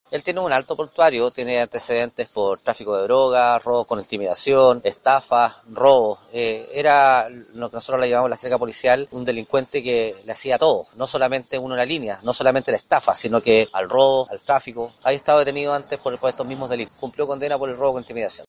La autoridad policial, se refirió a los antecedentes del excarabinero, quien además de ser dado de baja, cumplió condena y fue detenido tras ser encontrado en unas cabañas en Concón.